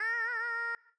Audio / SFX / Characters / Voices / BardHare / BardHare_08.wav